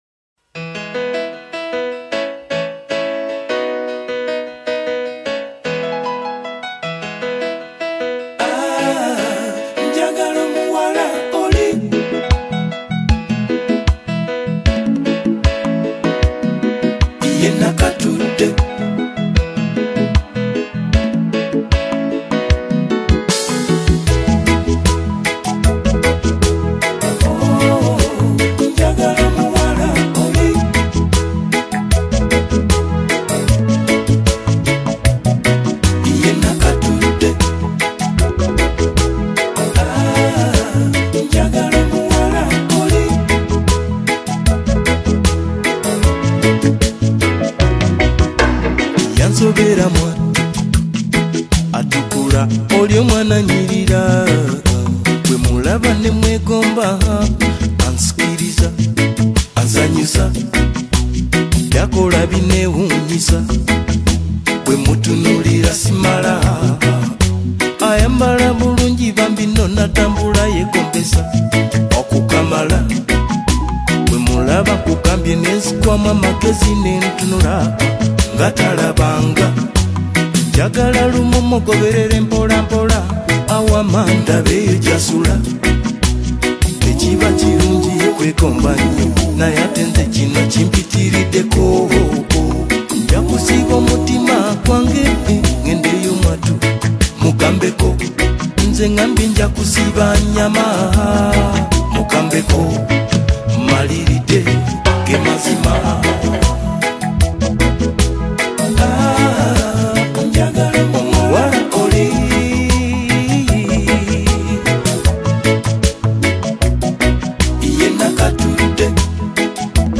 Genre: Ragga